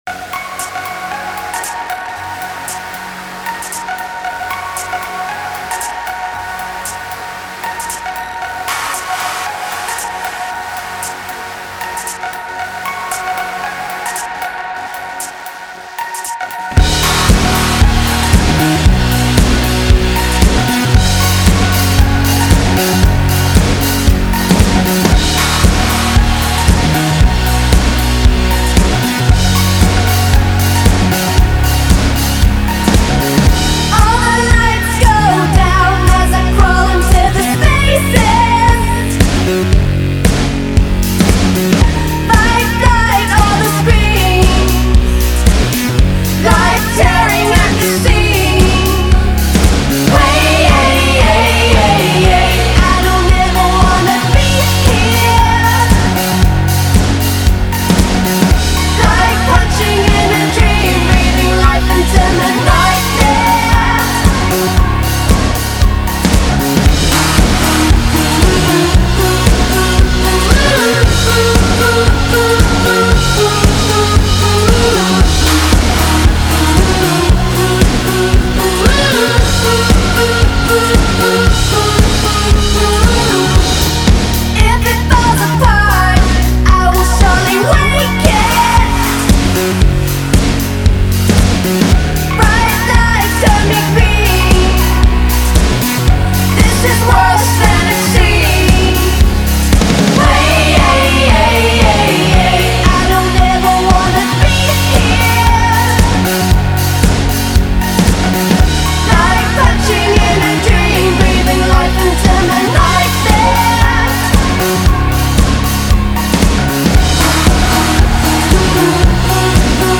its a banger…